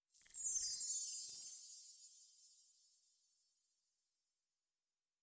Magic Chimes 07.wav